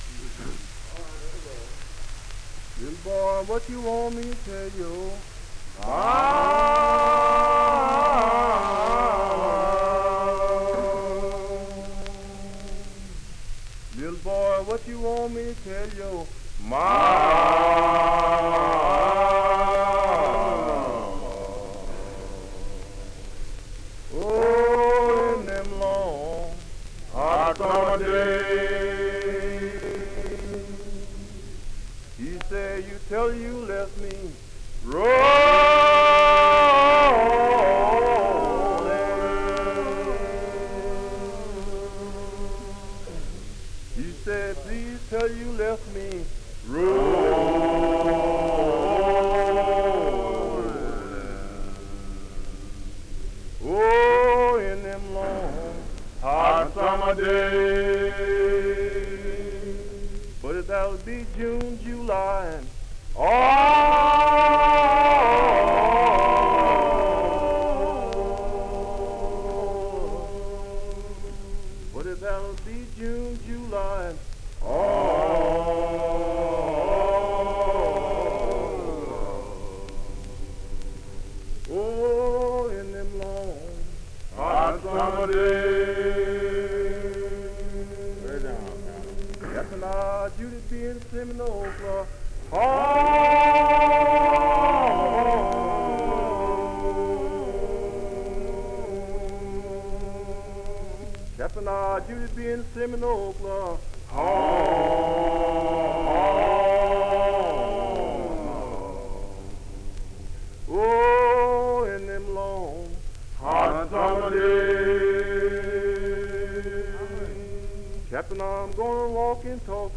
and a group of convicts from Clemens State Farm, Brazoria, Texas, performer.
The John and Ruby Lomax 1939 Southern States Recording Trip, Library of Congress.